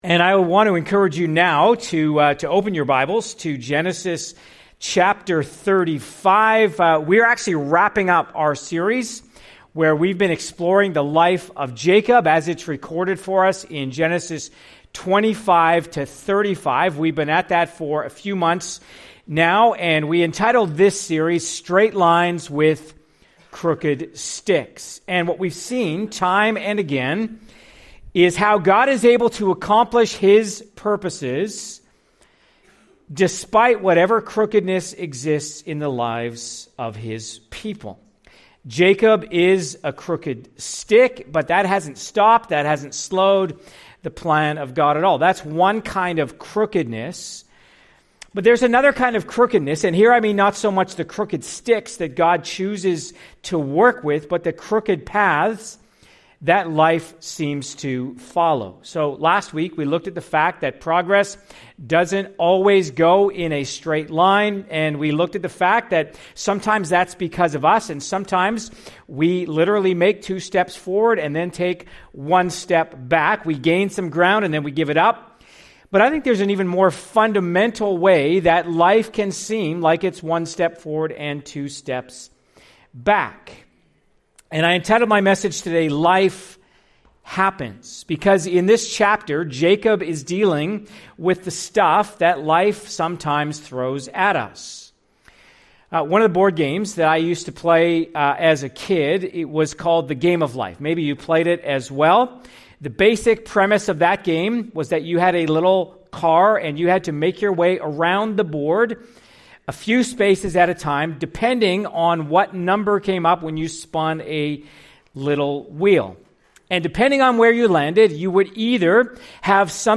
Part of our series, “ Straight Lines with Crooked Sticks ,” following the life of Jacob in the book of Genesis. CLICK HERE for other sermons from this series.